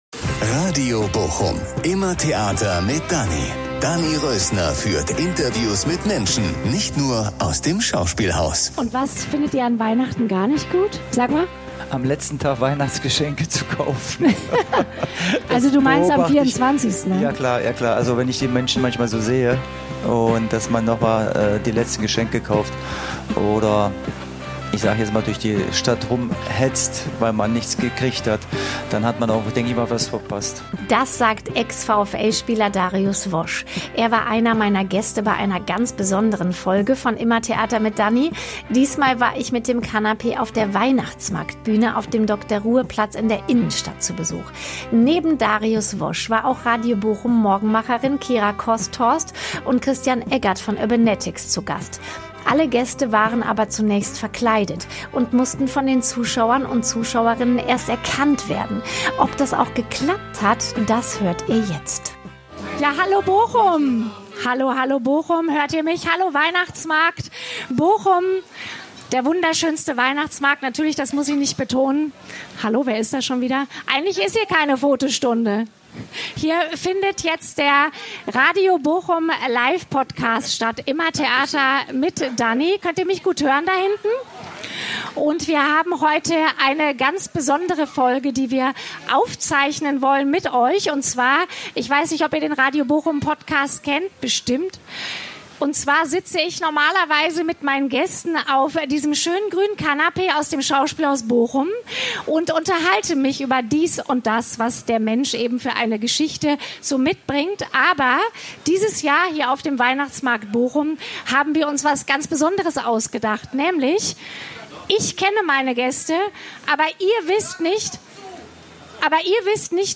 Dort hatte sie drei Bochumer Persönlichkeiten zu Gast, die das Publikum zunächst erraten mussten. Ob das gelungen ist, erfahrt ihr in der aktuellen Folge.